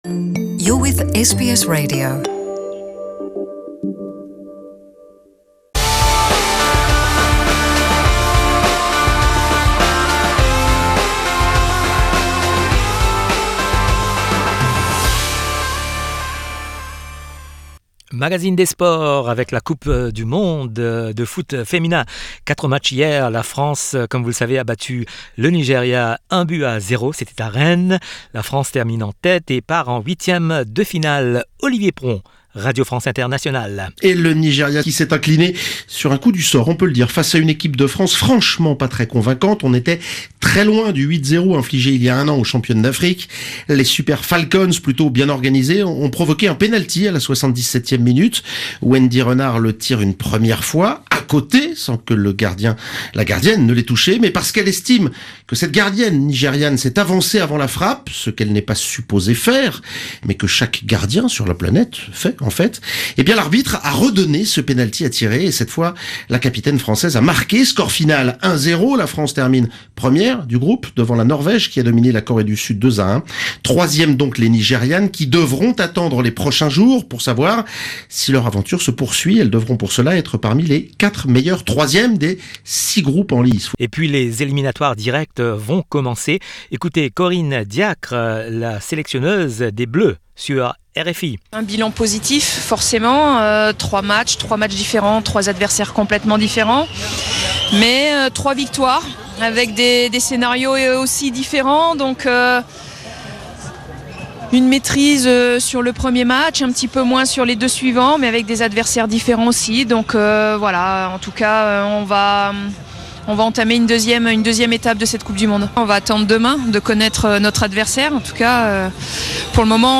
Le journal des sports du 18/06/2019